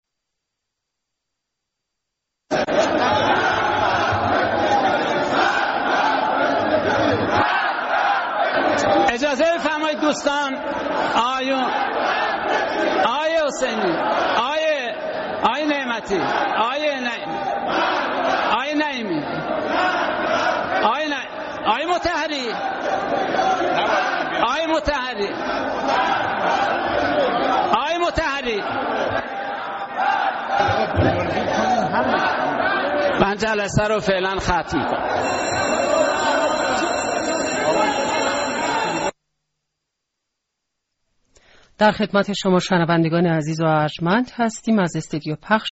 بخشی دیگر از سخنان علی مطهری و اعتراضات به او در مجلس. ۲۱ دی